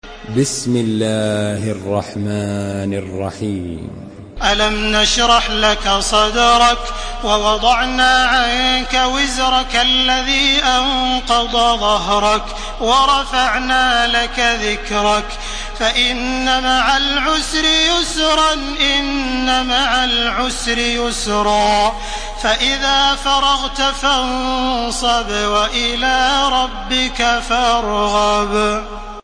Surah الشرح MP3 in the Voice of تراويح الحرم المكي 1431 in حفص Narration
Listen and download the full recitation in MP3 format via direct and fast links in multiple qualities to your mobile phone.
مرتل حفص عن عاصم